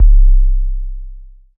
Blimp808_YC.wav